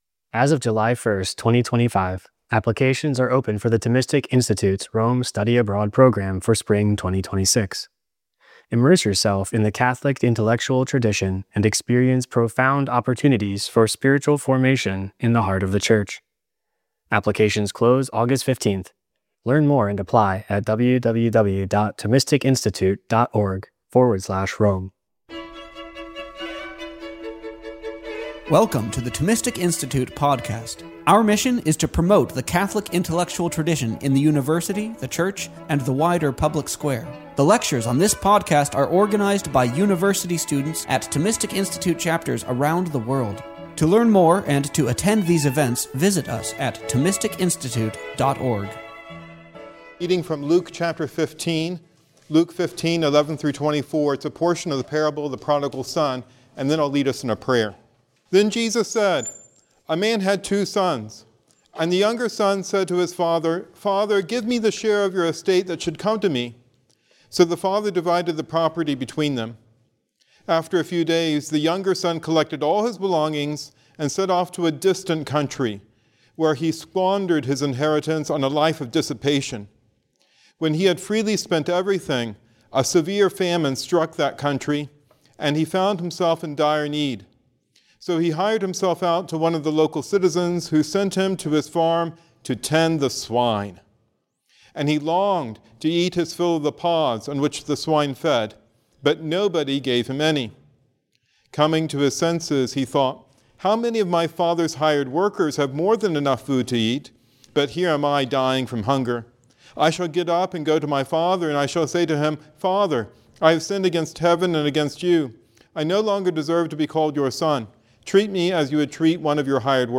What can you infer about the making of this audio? This lecture was held at St. Gretrude's parish on October 9th, 2018. It was cosponsored by the Aquinas Society of Cincinnati and the Thomistic Institute.